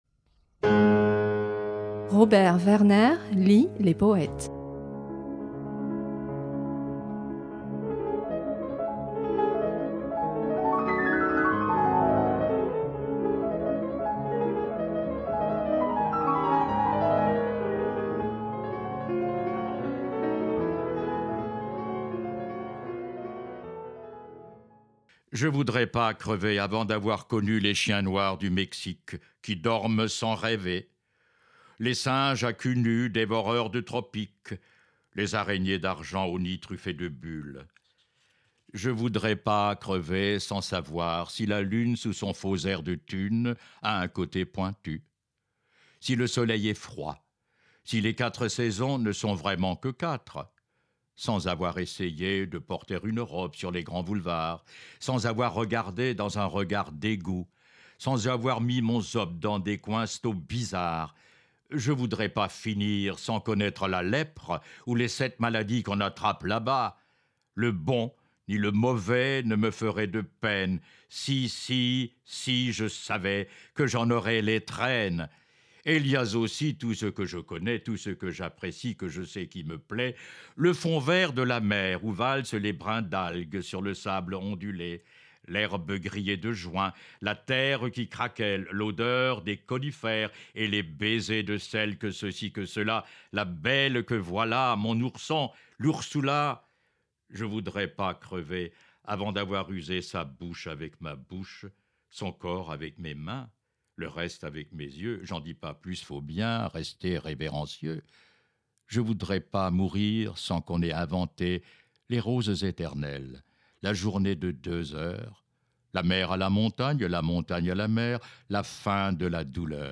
À voix lue